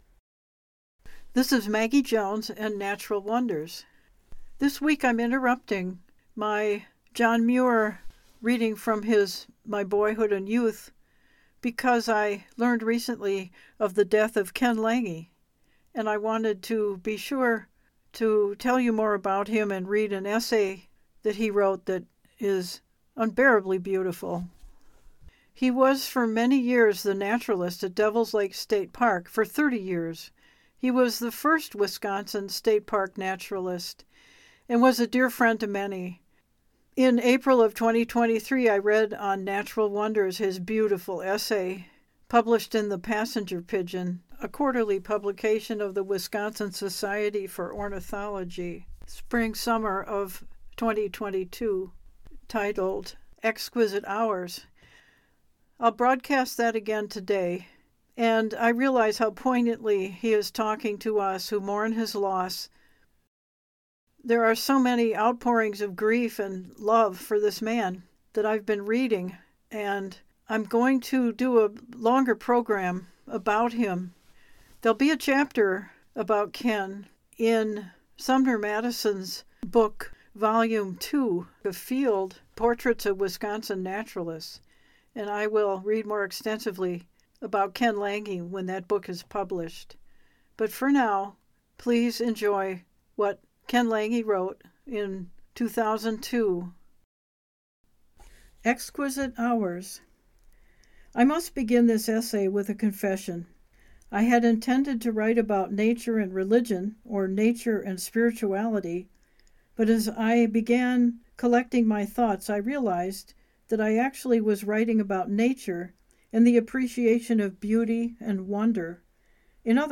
I interrupt my reading of Muir to talk about and read an essay